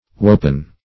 wopen - definition of wopen - synonyms, pronunciation, spelling from Free Dictionary Search Result for " wopen" : The Collaborative International Dictionary of English v.0.48: Wopen \Wo"pen\, obs. p. p. of Weep .